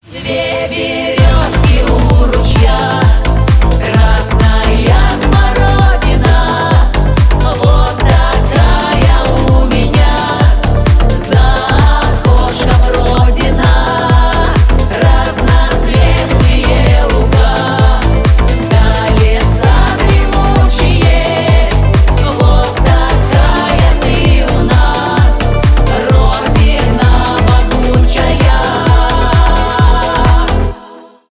народные , поп